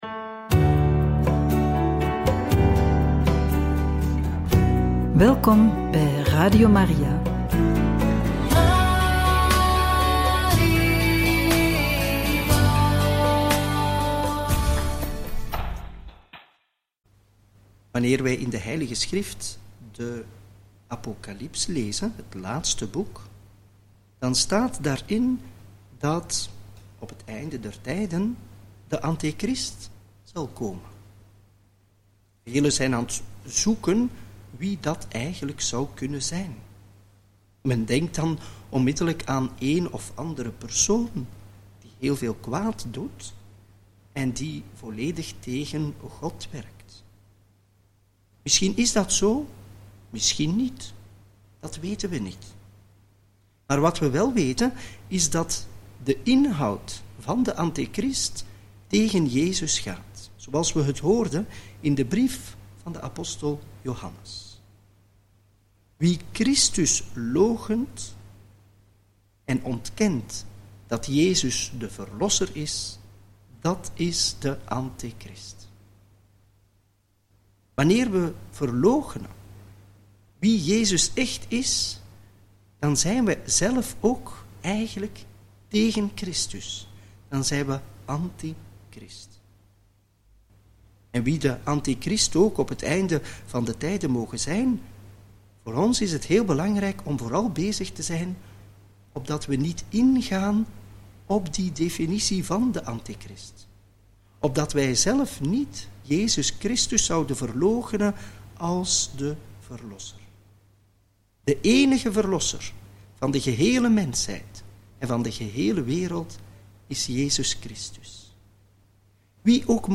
Homilie bij het Evangelie van donderdag 2 januari 2025 - Joh. 1, 19-28